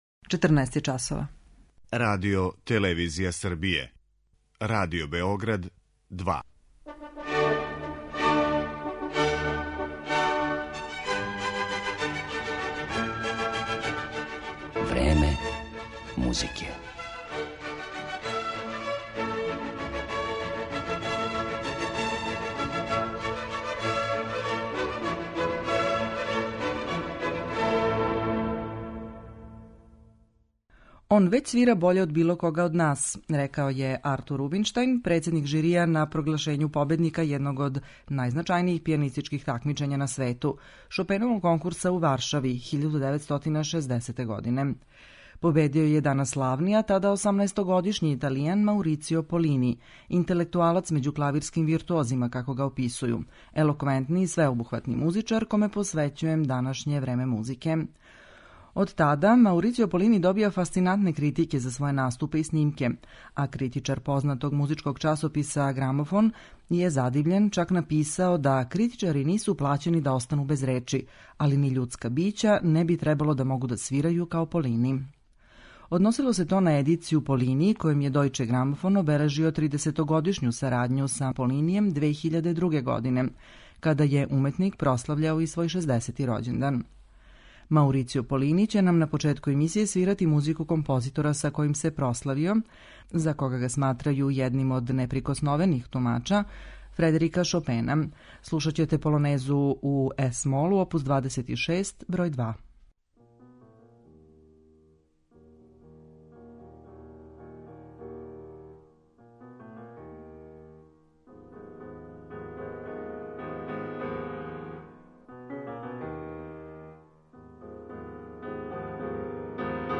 У 'Времену музике' - Маурицио Полини
Овај славни италијански солиста ће изводити композиције Фредерика Шопена, Јоханеса Брамса, Игора Стравинског, Лудвига ван Бетовена и Роберта Шумана.